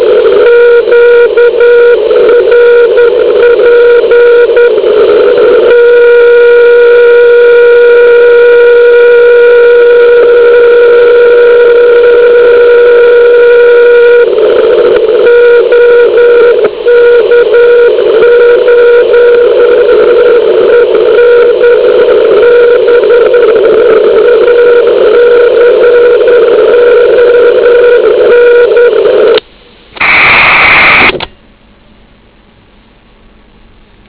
Porovnejte signál z OK0EN přijímaný na FT900 s CW filtrem 250 Hz na relativně kvalitní anténu.